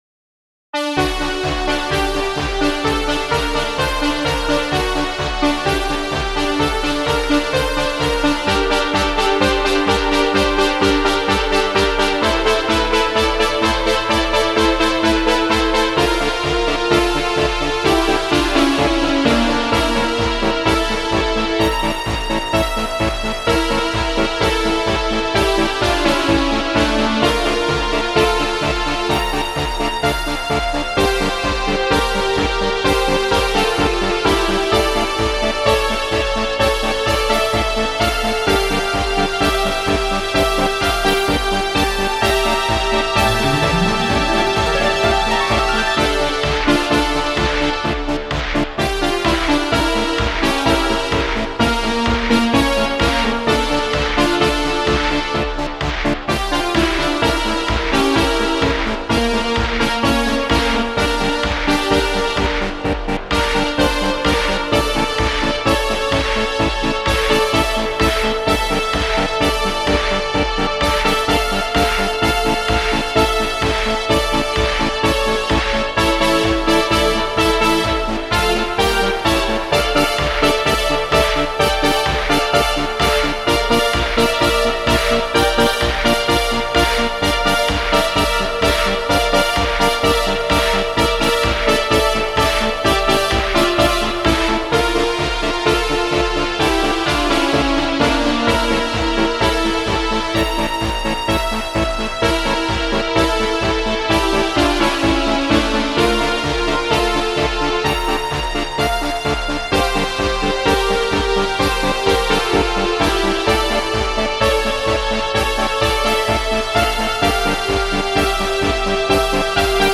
Spacesynth Trance Techno Relax
Meditative Newage Space